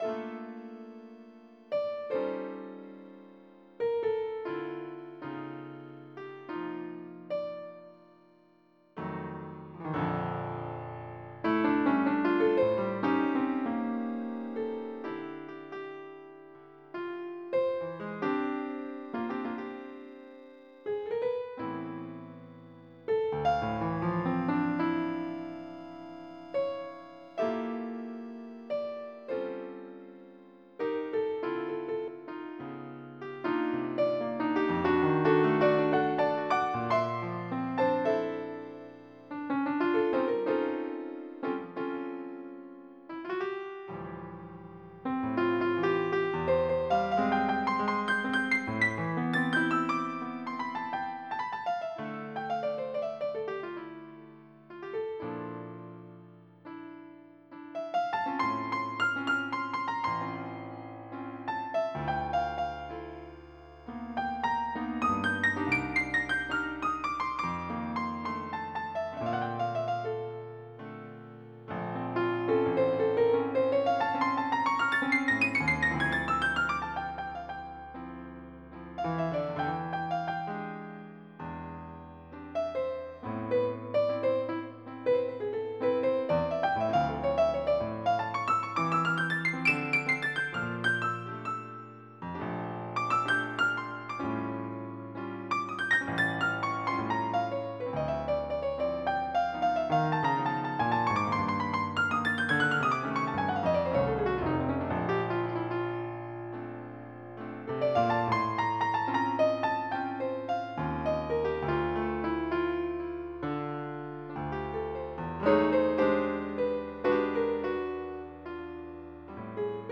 MIDI Music File
JAZZ09.mp3